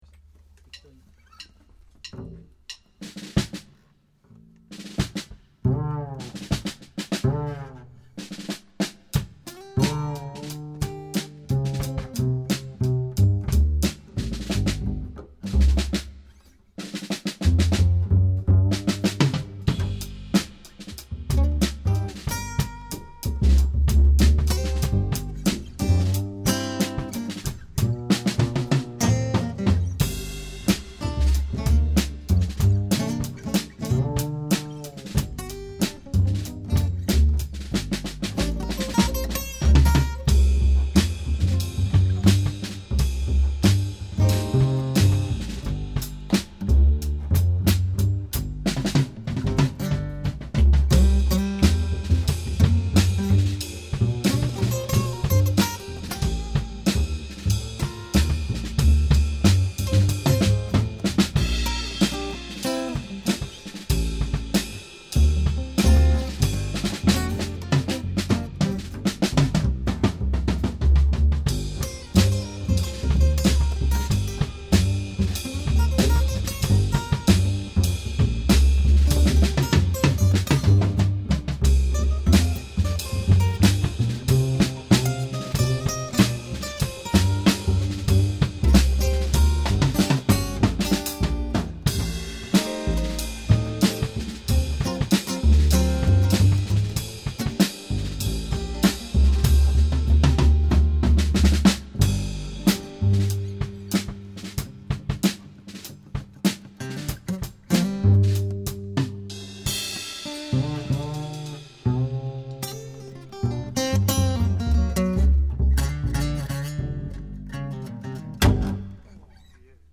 テイクワンのみ！